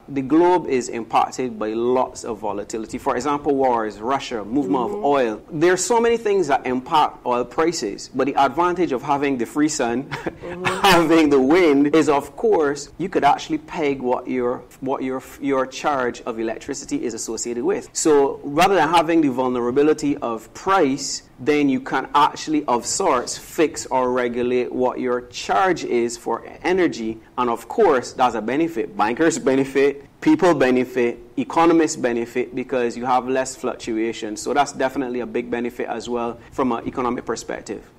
CARICOM Energy Month – A Panel Discussion